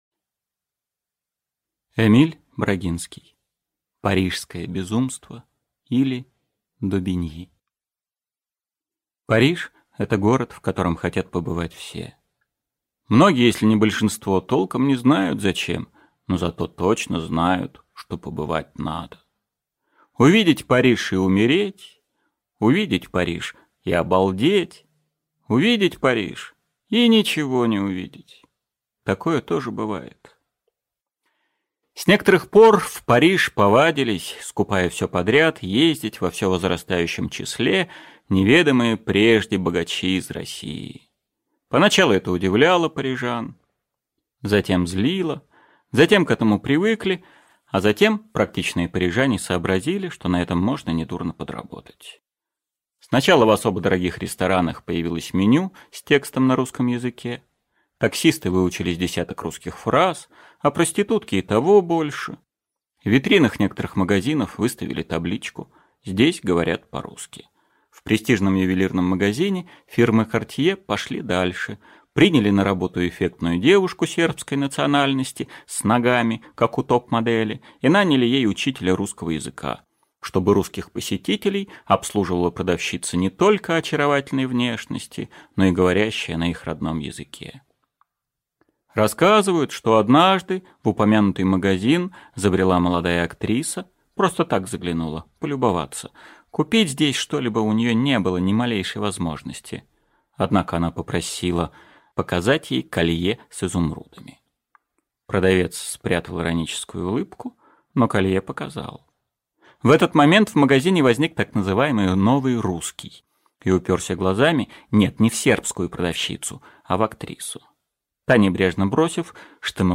Аудиокнига Парижское безумство, или Добиньи | Библиотека аудиокниг
Aудиокнига Парижское безумство, или Добиньи Автор Эмиль Брагинский Читает аудиокнигу Евгений Лебедев.